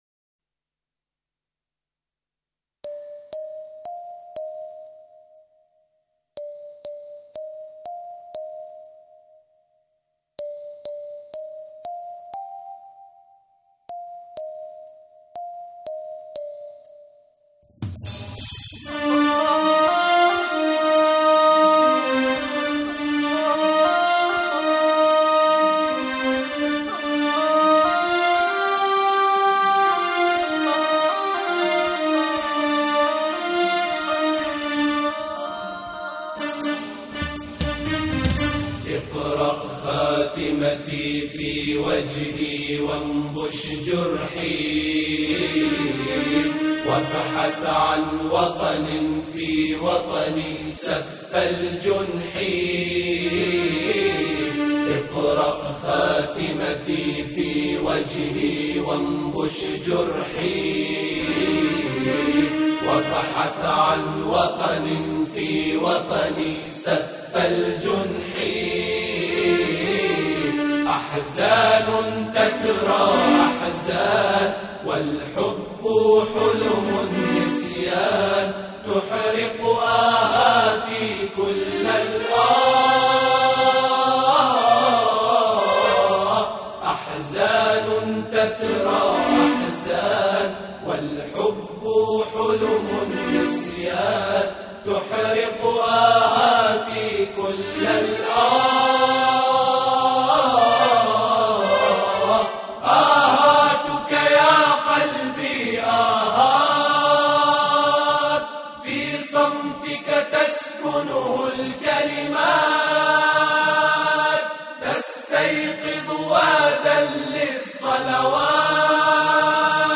أناشيد بحرينية
انشودة وطنية